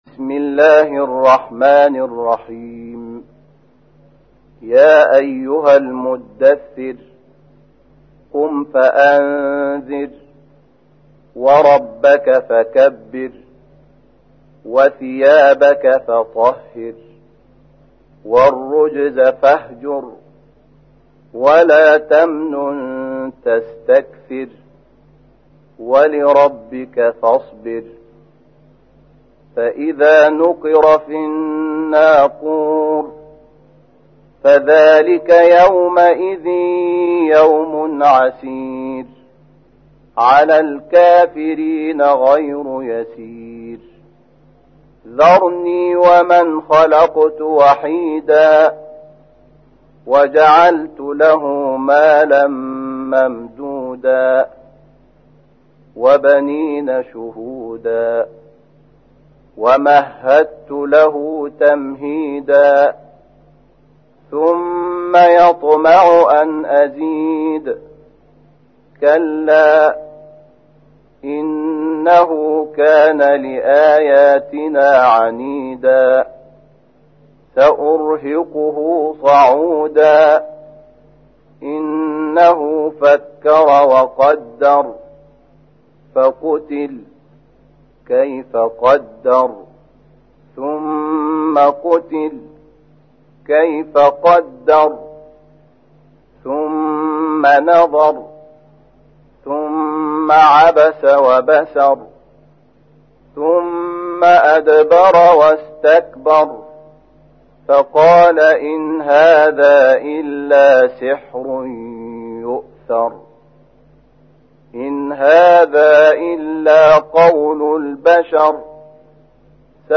تحميل : 74. سورة المدثر / القارئ شحات محمد انور / القرآن الكريم / موقع يا حسين